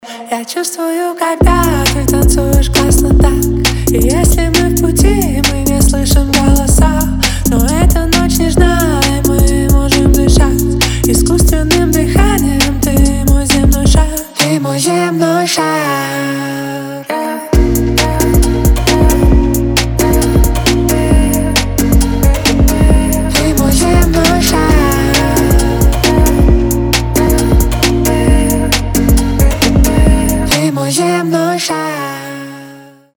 • Качество: 320, Stereo
лирика
Хип-хоп
dance